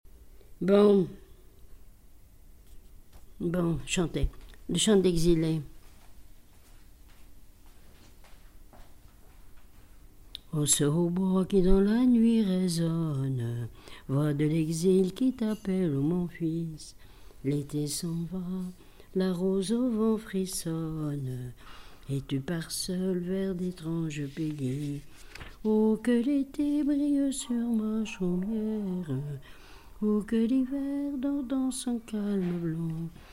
témoignage et chansons
Pièce musicale inédite